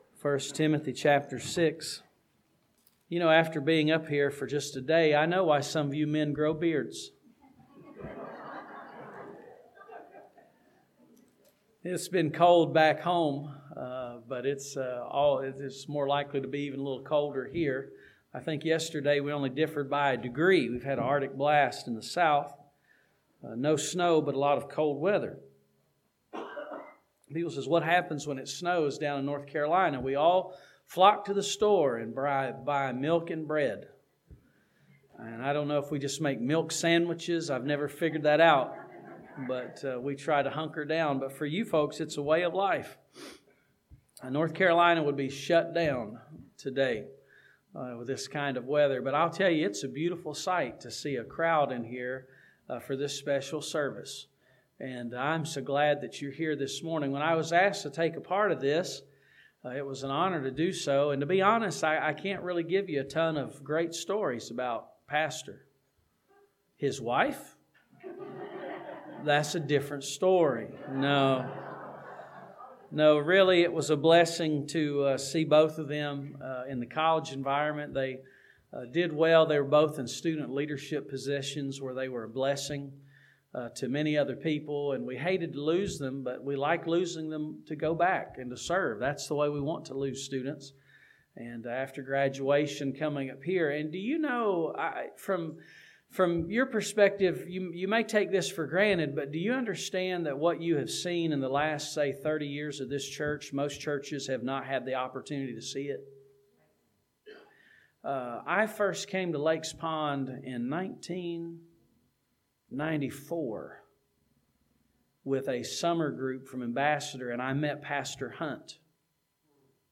This sermon from 1 Timothy chapter 6 challenges the man of God to flee, follow, fight and finish well in the ministry.